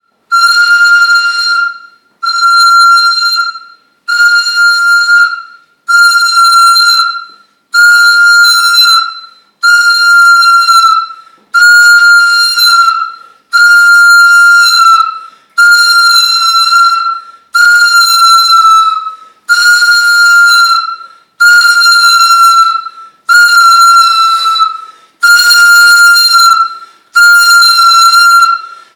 Esta pieza está rota en su extrema inferior, por lo que no se ha logrado alcanzar todo su potencial sonoro. Su tubo complejo permite el sonido “acatarrado” característico de este conjunto organológico, vigente hasta nuestros días en el complejo ritual de los “bailes chinos” de la zona central.
Catarra. Audio